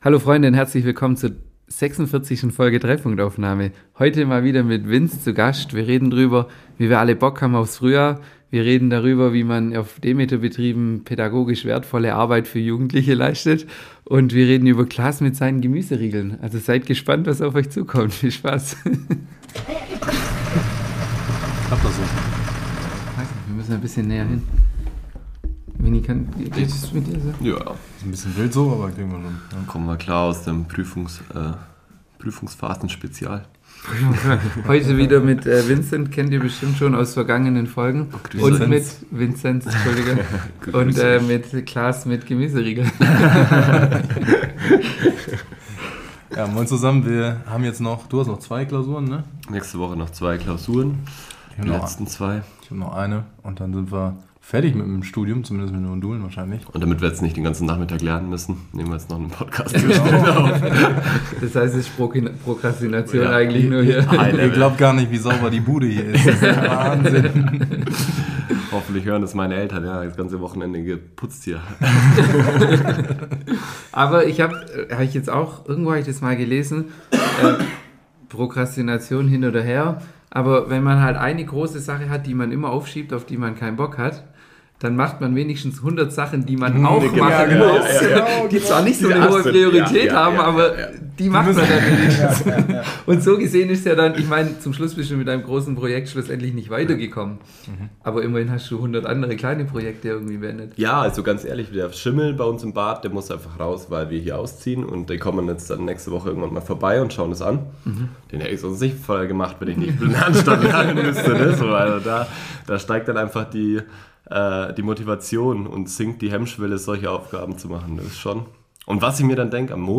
Mal wieder viel gelacht und über alles Mögliche geredet.